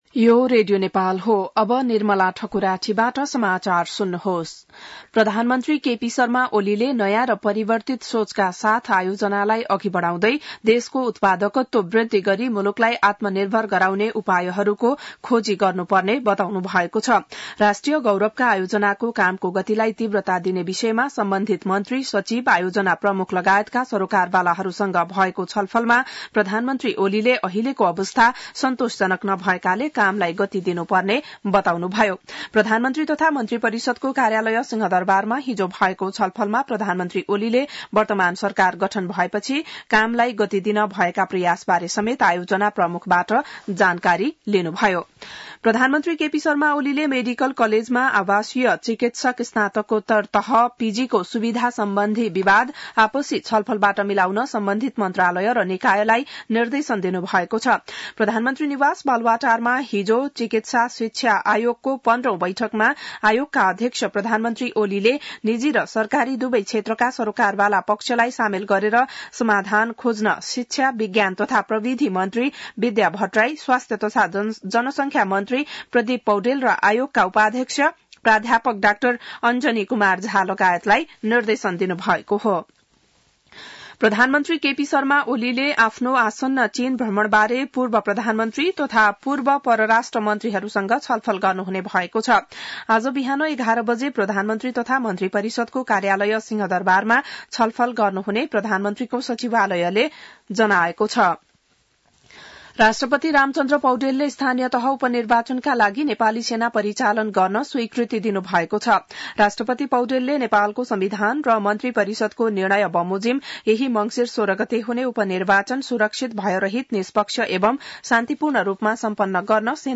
बिहान १० बजेको नेपाली समाचार : ११ मंसिर , २०८१